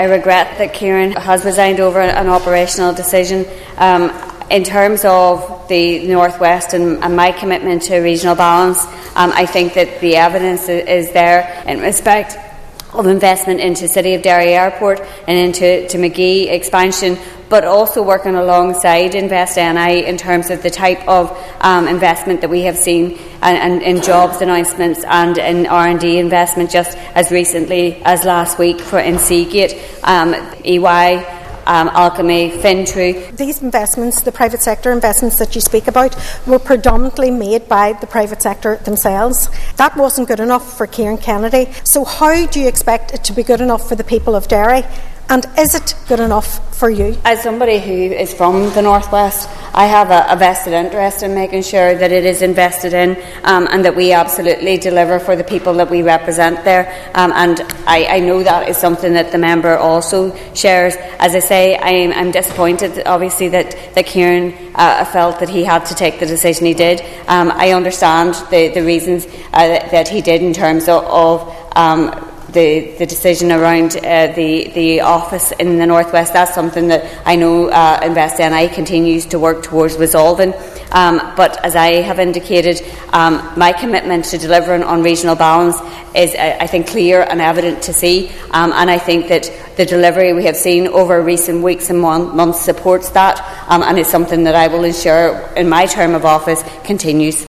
Minister Archibald told the Assembly it’s a regrettable situation……….